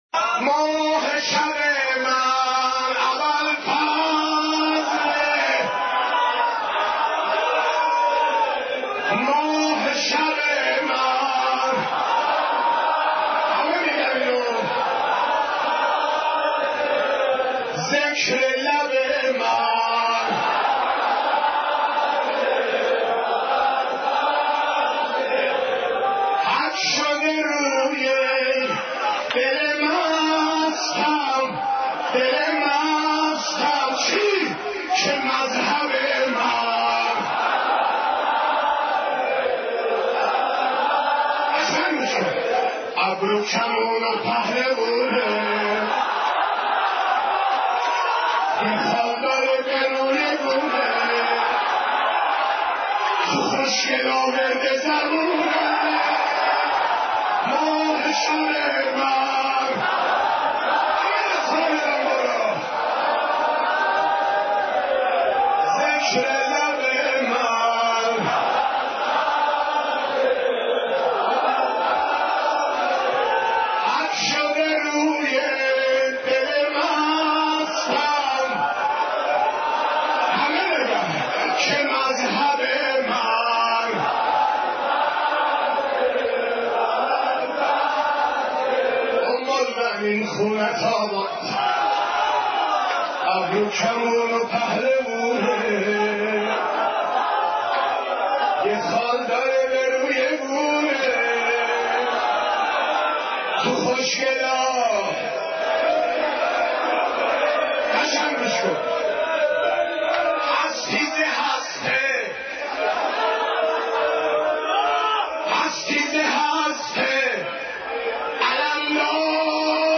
حضرت عباس ع ـ شور 11